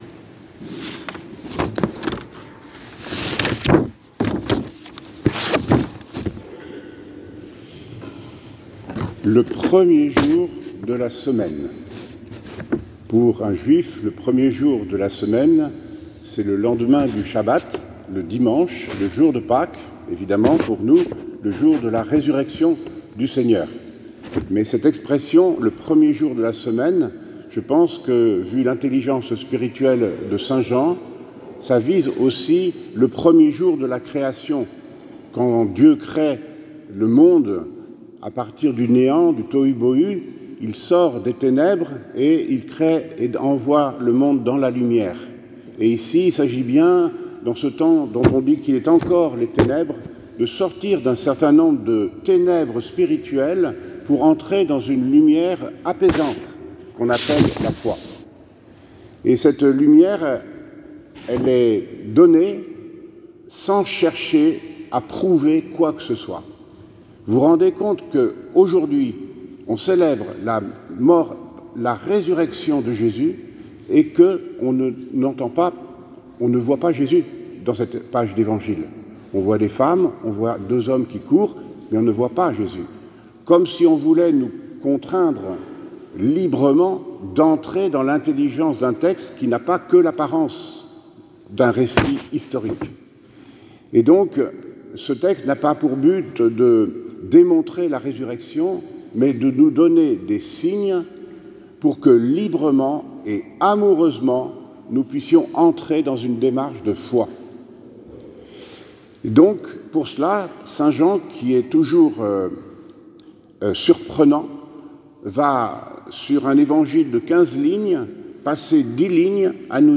Homélie de Mgr Philippe Marsset, le jour de Pâques